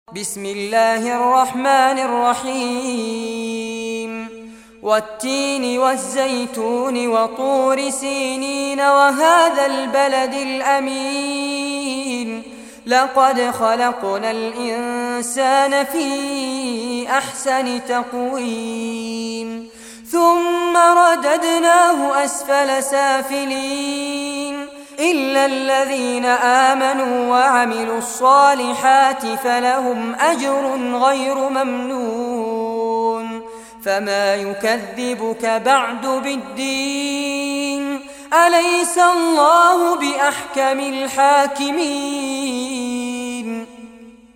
Surah At-Tin Recitation by Fares Abbad
Surah At-Tin, listen or play online mp3 tilawat / recitation in Arabic in the beautiful voice of Sheikh Fares Abbad.